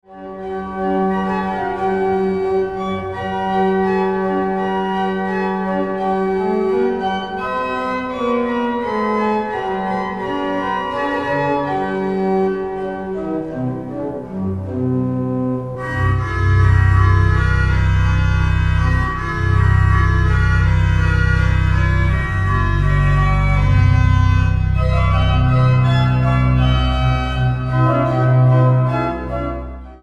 Genre: Christmas